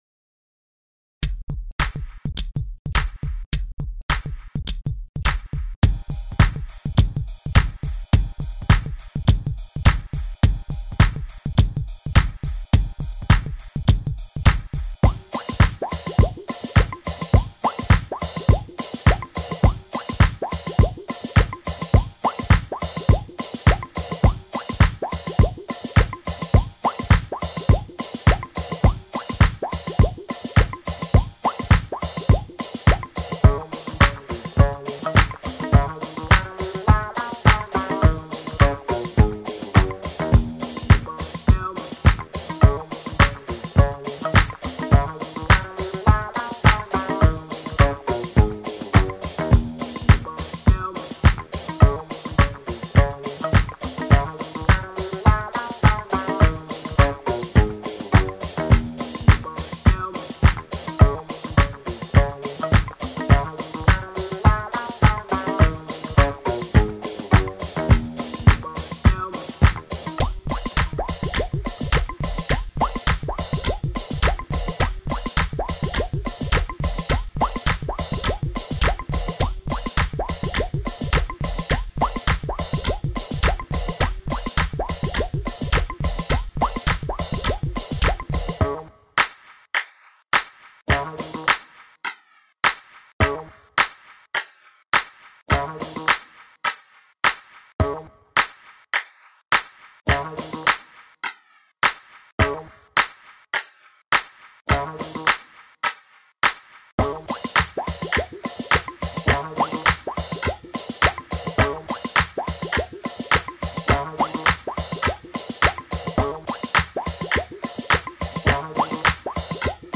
funky diva's from outer space